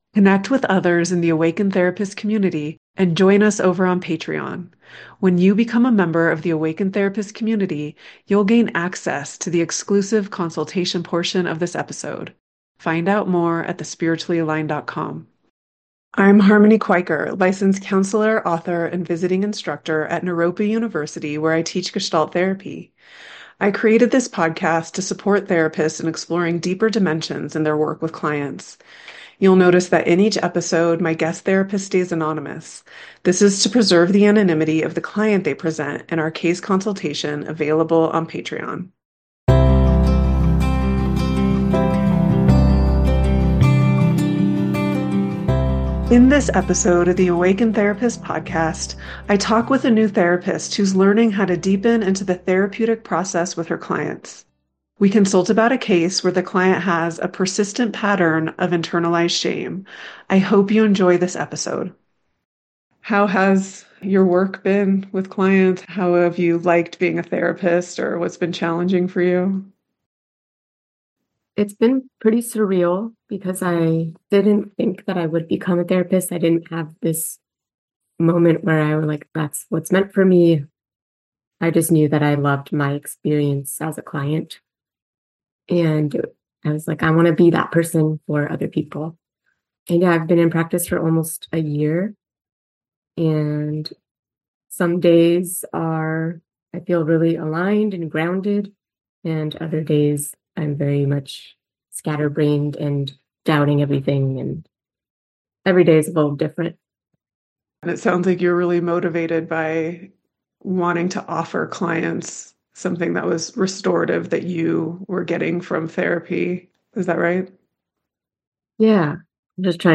The-Awakened-Therapist-Podcast-Conversation-s1e06-Working-with-Shame.mp3